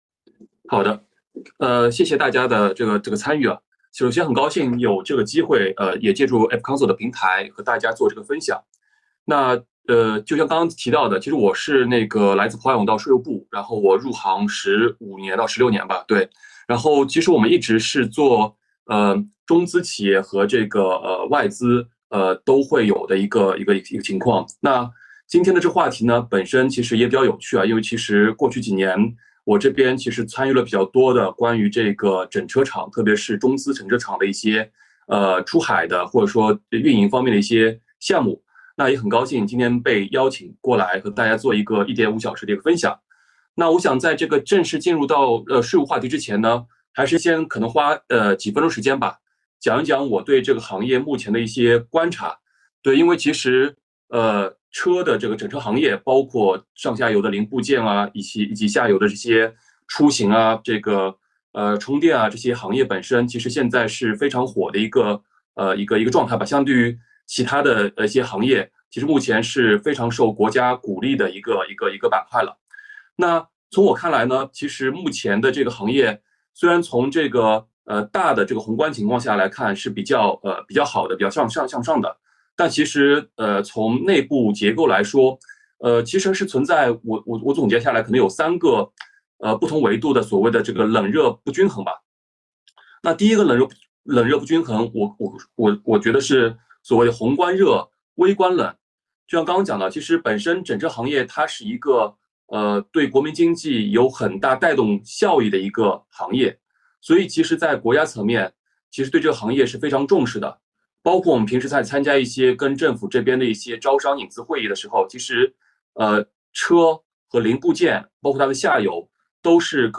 视频会议
互动问答